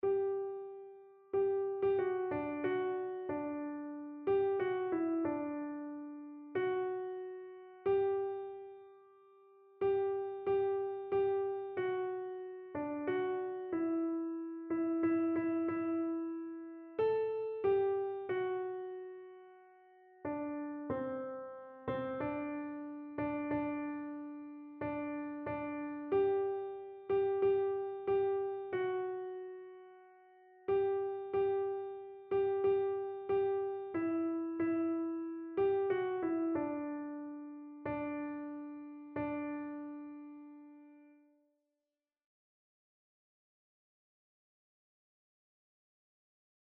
Notensatz 1 (4 Stimmen gemischt)
Einzelstimmen (Unisono)